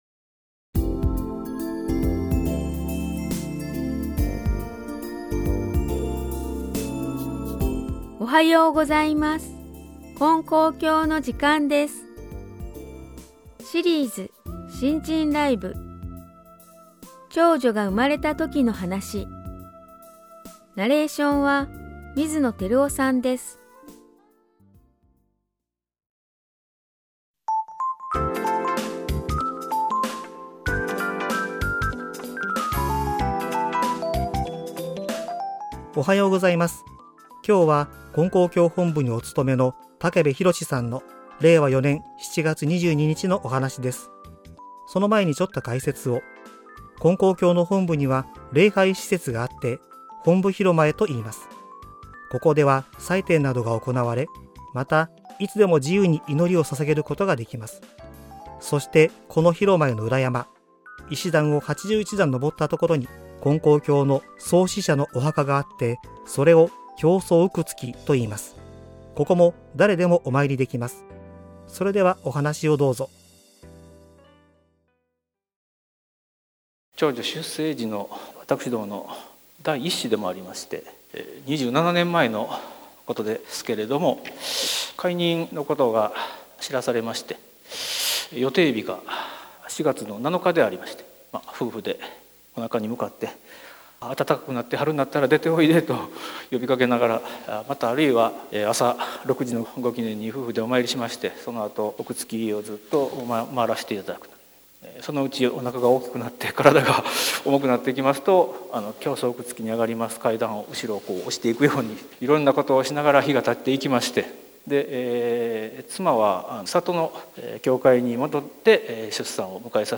●信心ライブ